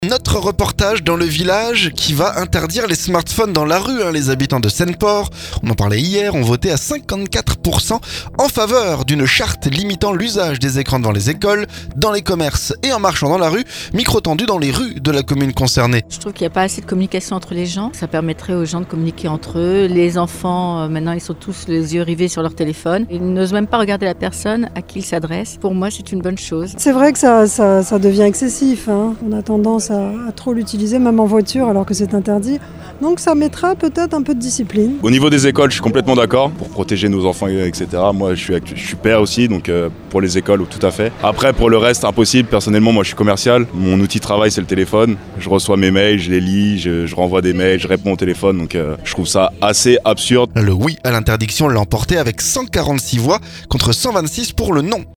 SEINE-PORT - Reportage dans la ville qui va interdire les écrans dans la rue
Les habitants de Seine-Port, on en parlait lundi, ont voté à 54% en faveur d'une charte limitant l'usage des écrans devant les écoles, dans les commerces et en marchant dans la rue. Micro tendu dans les rues de la commune concernée.